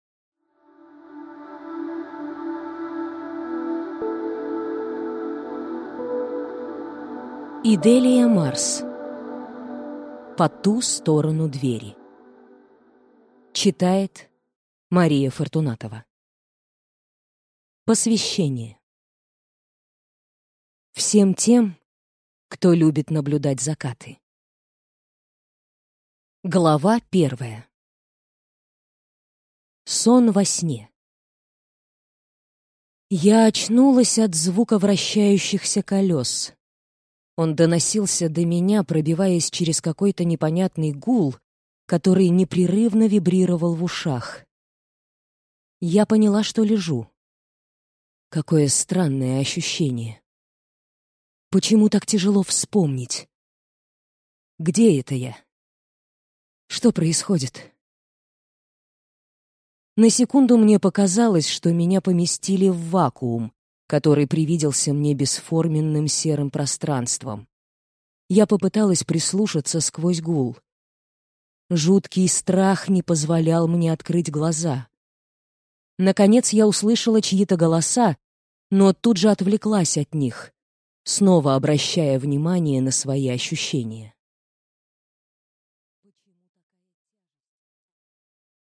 Аудиокнига По Ту Сторону Двери | Библиотека аудиокниг